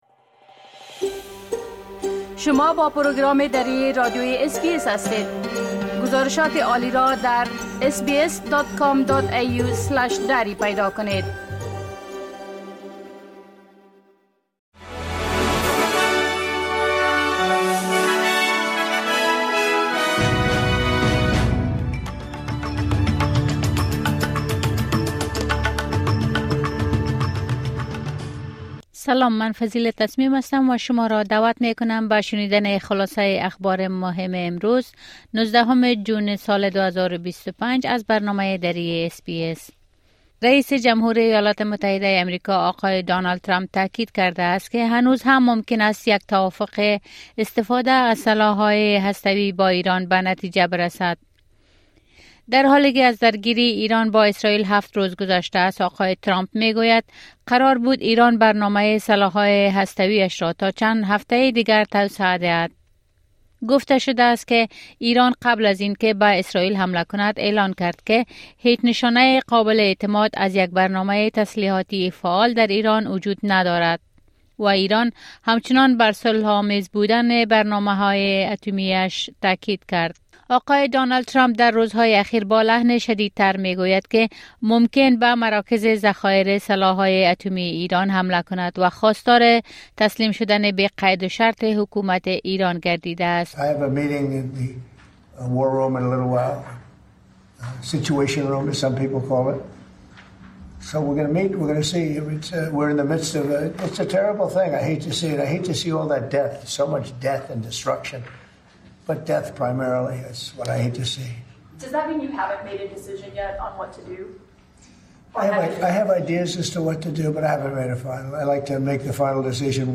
خلاصه مهمترين خبرهای روز از بخش درى راديوى اس‌بى‌اس | ۱۹ جون